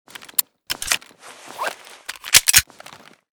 glock_reload_empty.ogg.bak